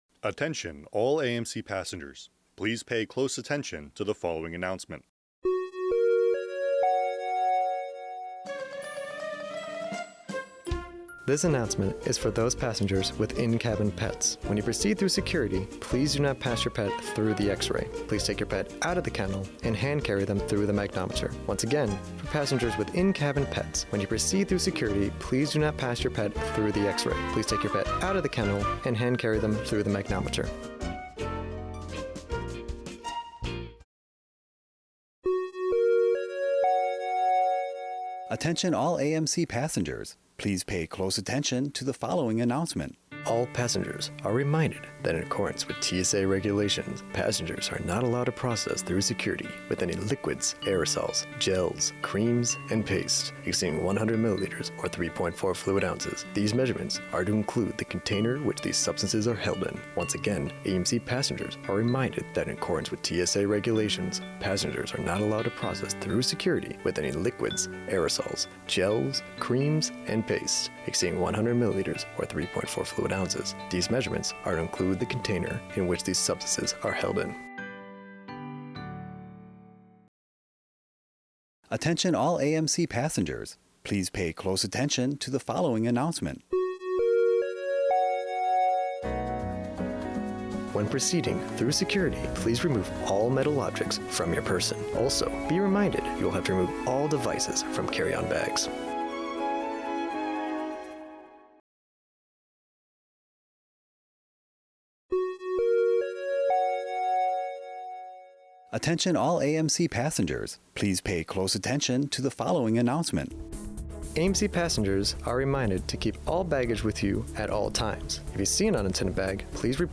This readout contains messaging about TSA Regulations, securing your luggage, protecting your pets and tips for moving through security efficiently, narrated by service members assigned to Media Bureau Japan.